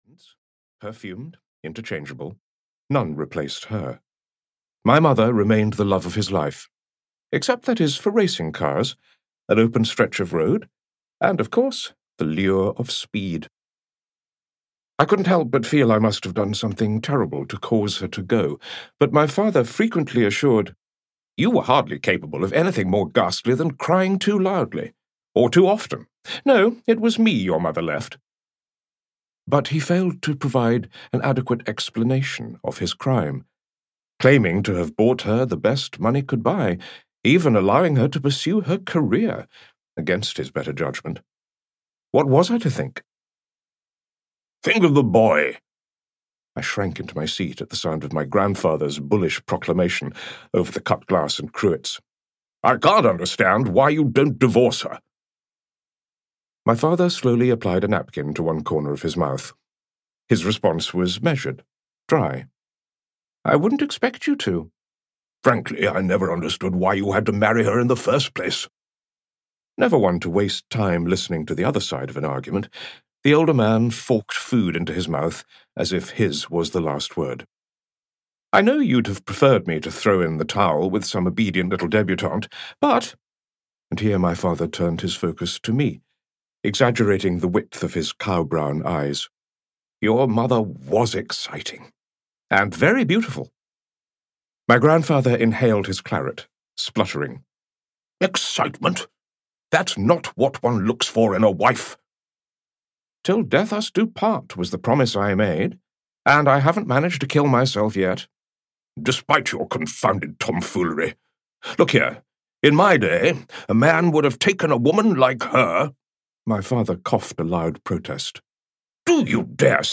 I Stopped Time (EN) audiokniha
Ukázka z knihy